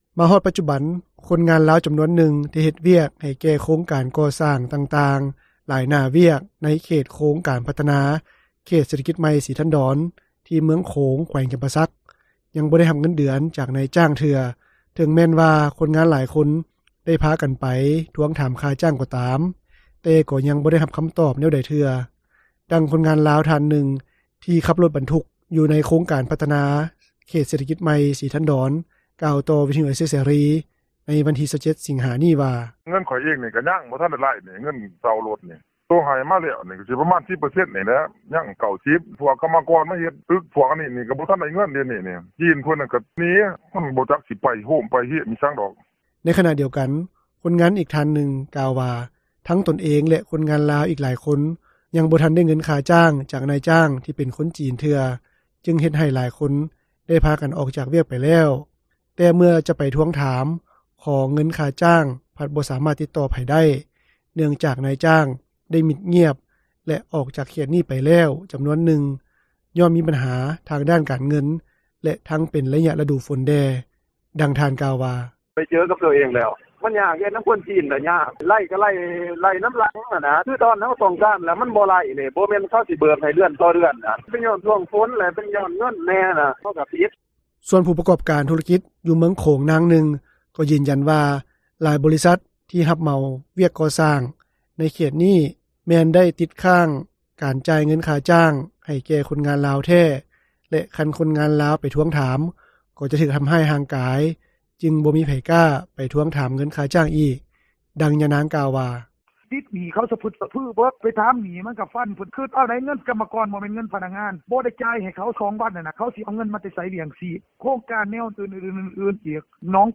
ມາຮອດປັດຈຸບັນ, ຄົນງານລາວ ຈຳນວນໜຶ່ງ ທີ່ເຮັດວຽກ ໃຫ້ແກ່ໂຄງການກໍ່ສ້າງຕ່າງໆ ຫຼາຍໜ້າວຽກ ໃນເຂດໂຄງການພັດທະນາ ເຂດເສດຖະກິດໃໝ່ ສີທັນດອນ ທີ່ເມືອງໂຂງ ແຂວງຈຳປາສັກ ຍັງບໍ່ໄດ້ຮັບເງິນເດືອນ ຈາກນາຍຈ້າງເທື່ອ ເຖິງແມ່ນວ່າ ຄົນງານຫຼາຍຄົນ ໄດ້ພາກັນໄປ ທວງຖາມຄ່າຈ້າງກໍຕາມ, ແຕ່ກໍຍັງບໍ່ໄດ້ຮັບຄຳຕອບ ແນວໃດເທື່ອ. ດັ່ງຄົນງານລາວ ທ່ານໜຶ່ງ ທີ່ຂັບລົດບັນທຸກ ຢູ່ໃນໂຄງການພັດທະນາ ເຂດເສດຖະກິດໃໝ່ ສີທັນດອນ ກ່່າວຕໍ່ວິທຍຸເອເຊັຽເສຣີ ໃນວັນທີ 27 ສິງຫາ ນີ້ວ່າ:
ສ່ວນຜູ້ປະກອບການທຸລະກິດ ຢູ່ເມືອງໂຂງ ນາງໜຶ່ງ ກໍຢືນຢັນວ່າ ຫຼາຍບໍລິສັດ ທີ່ຮັບເໝົາວຽກກໍ່ສ້າງ ໃນເຂດນີ້ ແມ່ນໄດ້ຕິດຄ້າງ ການຈ່າຍເງິນຄ້າຈ້າງ ໃຫ້ແກ່ຄົນງານລາວແທ້ ແລະ ຄັນຄົນງານລາວ ໄປທວງຖາມ ກໍຈະຖືກທຳຮ້າຍຮ່າງກາຍ ຈຶ່ງບໍ່ມີໃຜກ້າ ໄປທວງຖາມເງິນຄ່າຈ້າງອີກ. ດັ່ງຍານາງກ່າວວ່າ:
ໃນຂະນະທີ່ ເມື່ອບໍ່ດົນມານີ້, ໂຄງການພັດທະນາ ເຂດເສດຖະກິດໃໝ່ ສີທັນດອນ ກໍໄດ້ຈ່າຍເງິນຄ່າຊົດເຊີຍ ແບບລ່ວງໜ້າ ໃຫ້ແກ່ຊາວບ້ານ ຈຳນວນ 108 ຄອບຄົວ ທີ່ຖືກຜົນກະທົບ ດ້ານທີ່ດິນ ຈາກໂຄງການຕ່າງໆ ທີ່ຢູ່ໃນເຂດດັ່ງກ່າວ ລວມມູນຄ່າທັງໝົດ ຫຼາຍກວ່າ 72 ຕື້ກີບ ແລະ 5.7 ລ້ານໂດ່ລ້າຣ໌ສະຫະຣັຖ ເປັນຕົ້ນ ໂຄງການສະໜາມກ໊ອຟ, ໂຄງການຕຶກແຄນຄູ່, ໂຄງການສາງສິນຄ້າ ແລະ ອື່ນໆ ພາຍຫຼັງທີ່ໄດ້ຕິດຄ້າງ ເປັນເວລາດົນເຕີບ, ເຊິ່ງຊາວບ້ານ ທີ່ໄດ້ຮັບຄ່າຊົດເຊີຍ ດັ່ງກ່າວນັ້ນ ຕ່າງກໍຮູ້ສຶກພໍໃຈ ແລະ ບໍ່ໄດ້ຮ້ອງຂໍ ຄ່າຊົດເຊີຍຕື່ມອີກ. ດັ່ງຊາວບ້ານ ທ່ານໜຶ່ງ ທີ່ຫາກໍໄດ້ຮັບຄ່າຊົດເຊີຍ ກ່າວວ່າ: